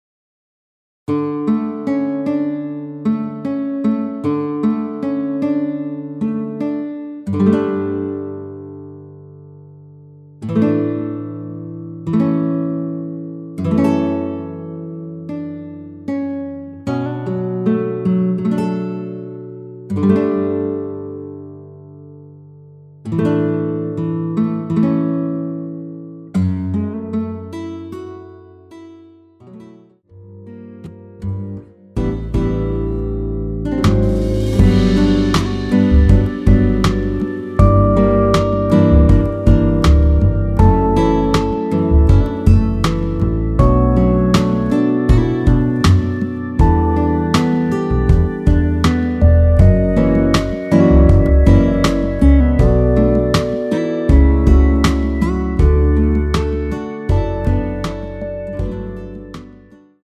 전주 없이 시작하는 곡이라 라이브 하기 좋게 전주 2마디 만들어 놓았습니다.(미리듣기 확인)
원키에서(-1)내린 MR입니다.
◈ 곡명 옆 (-1)은 반음 내림, (+1)은 반음 올림 입니다.
앞부분30초, 뒷부분30초씩 편집해서 올려 드리고 있습니다.